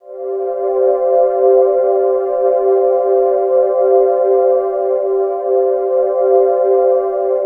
Index of /90_sSampleCDs/USB Soundscan vol.13 - Ethereal Atmosphere [AKAI] 1CD/Partition A/01-AMBIANT A